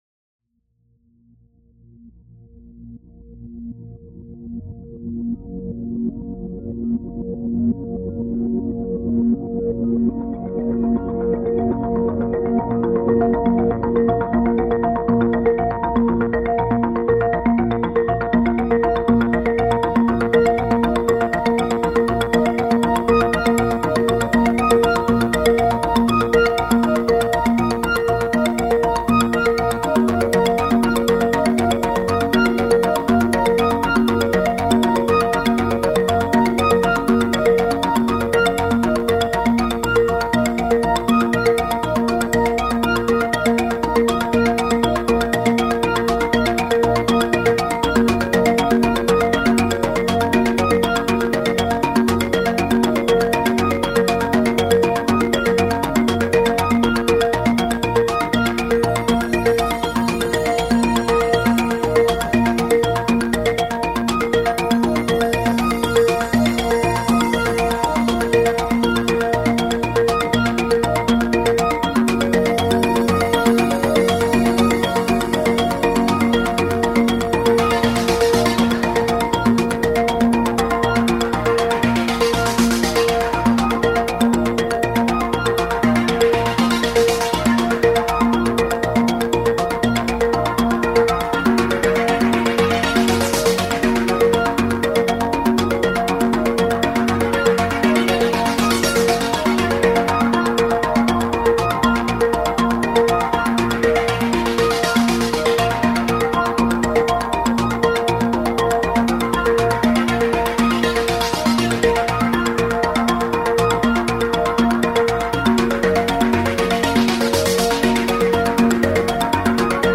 Ambient electronic track for podcast.